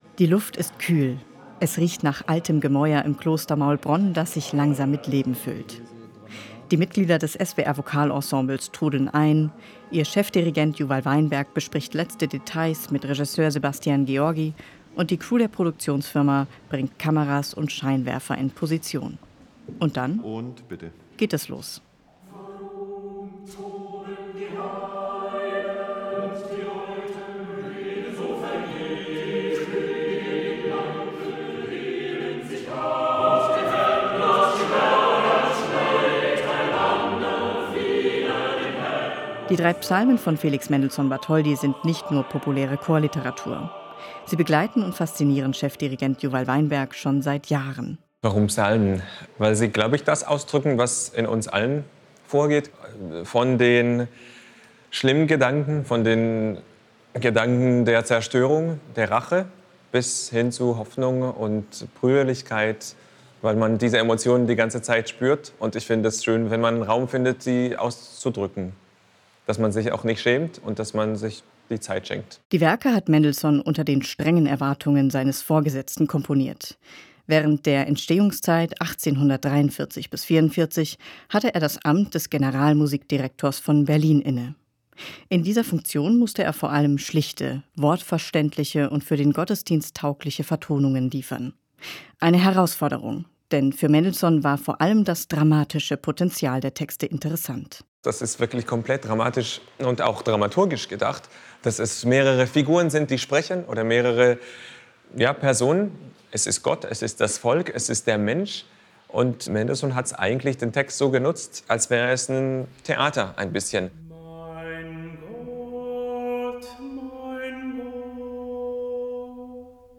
Das SWR Vokalensemble hat sie für eine besondere Videoproduktion eingesungen, und zwar im UNESCO-Welterbe, dem Kloster Maulbronn.